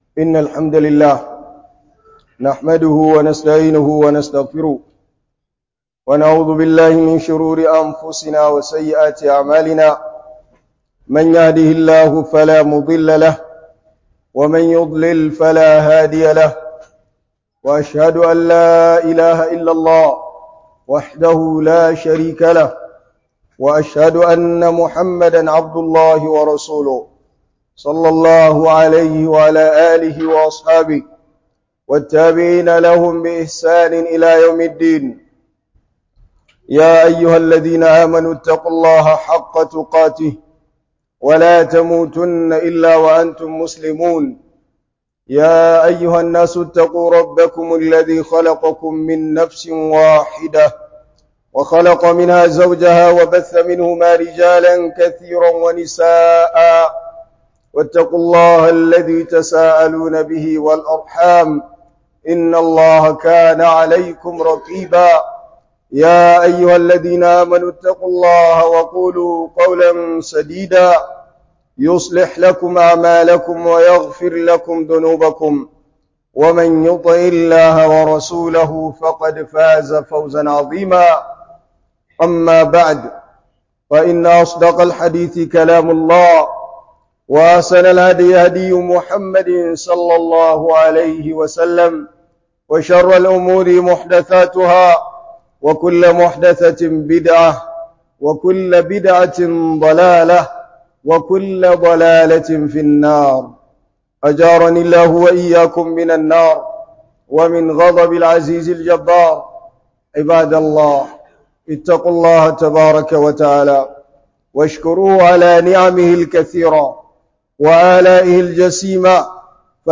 Hudubar Juma'a 14 Fév 2025